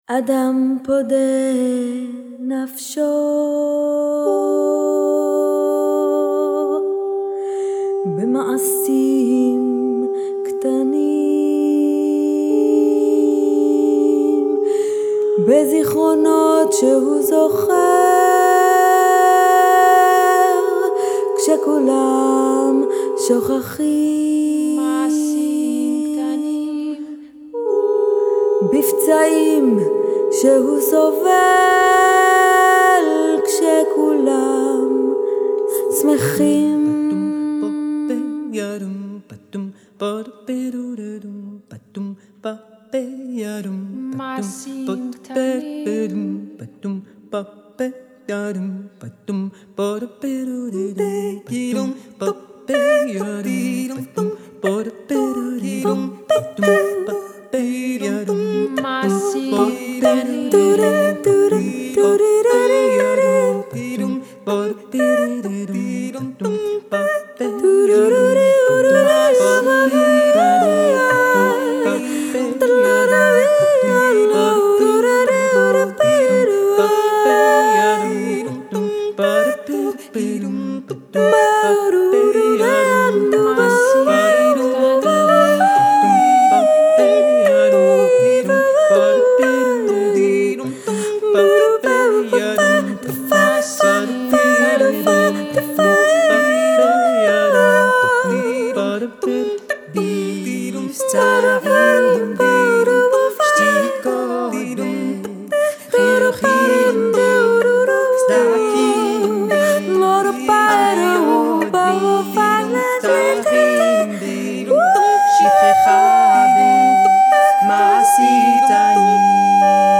an evocative a cappella vocal quartet
they fashion lush harmonies and strong rhythmic drive
Genre: Jazz, Vocal, A Capella